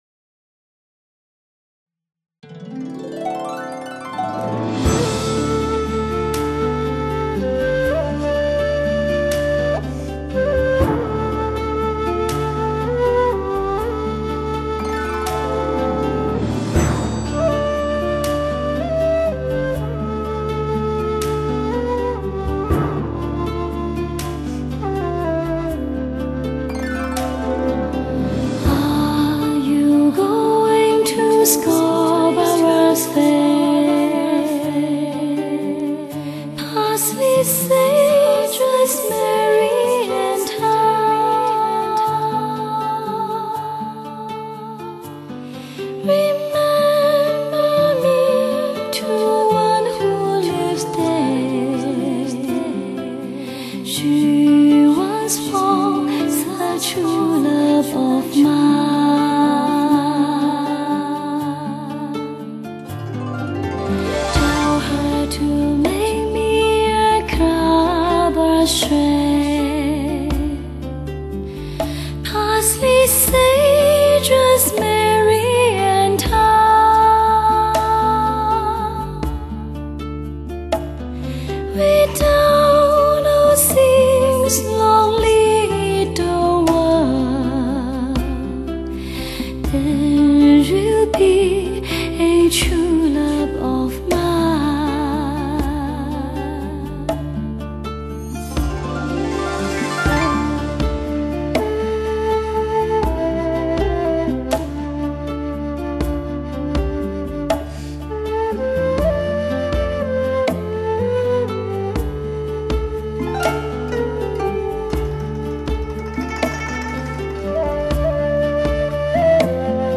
精彩发烧的女声，优美、圆润、感性十足的美音
（试听为低品质wma格式文件，下载为320k/mp3）